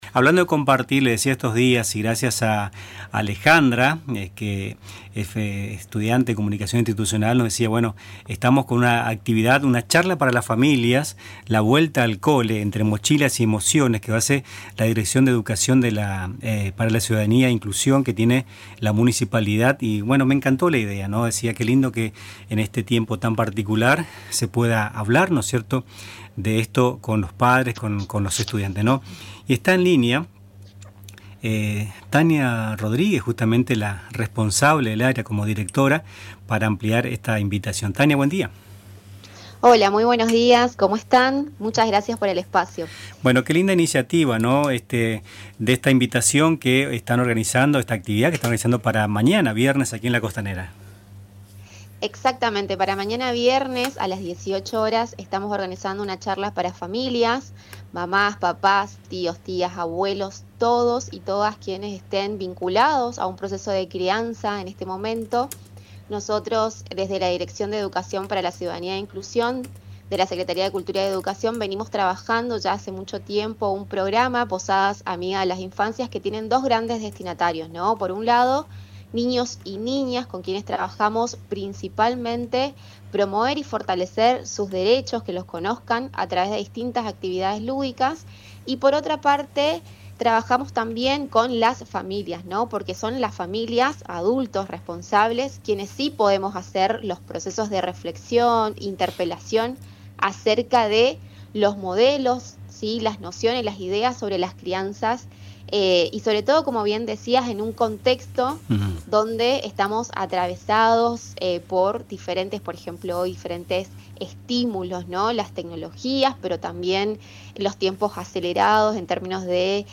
Entrevistas
Aquí, el audio de la entrevista realizada en Radio Tupambaé: